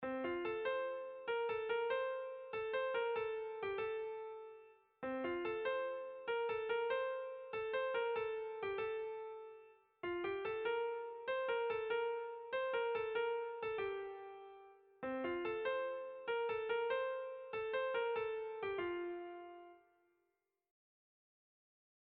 Irrizkoa
DINDIRIN DIN DIN leloarekin txandatzen da.
Seiko txikia (hg) / Hiru puntuko txikia (ip)
AABA